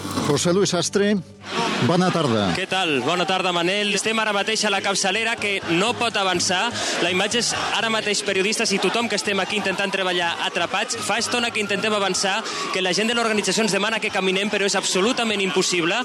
Informació des de la capçalera de la manifestació «Som una nació. Nosaltres decidim» en contra de la resolució del recurs d'inconstitucionalitat efectuat pel Tribunal Constitucional d'Espanya sobre el text de l'Estatut de Catalunya.
Informatiu